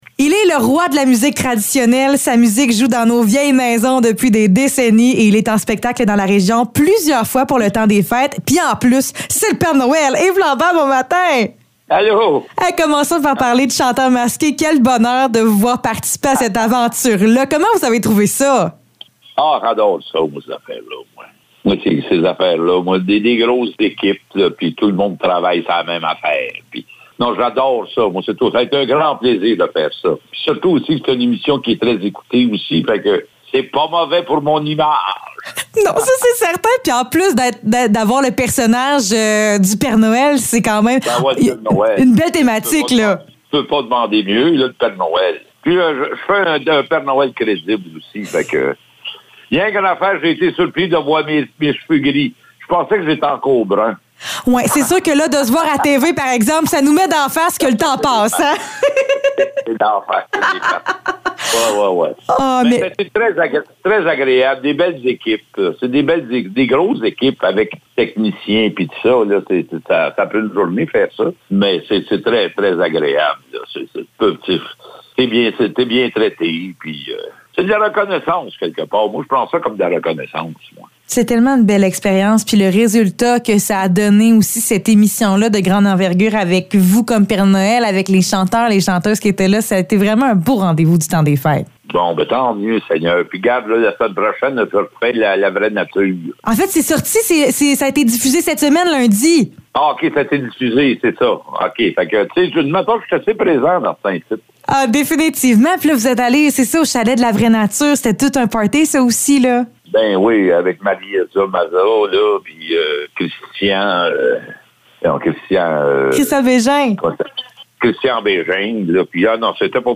Entrevue avec Yves Lambert